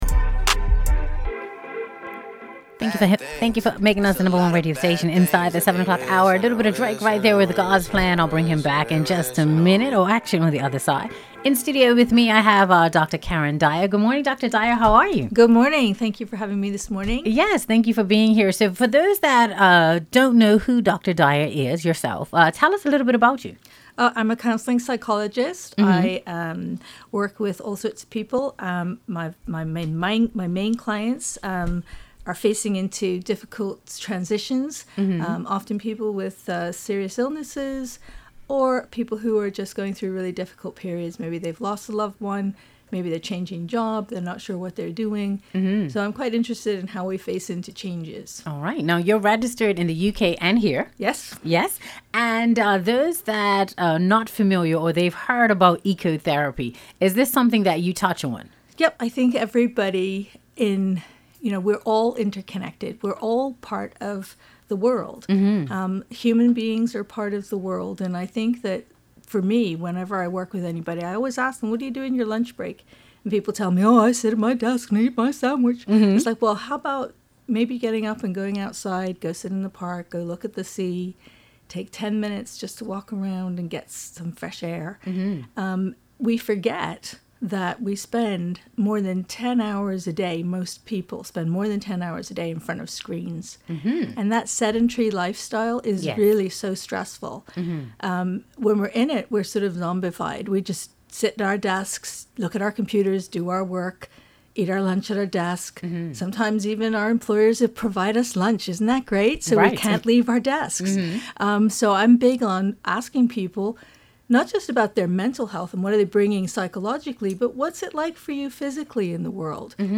Radio Interviews
BEST-INTERVIEW-APRIL-15TH-POWER-95.mp3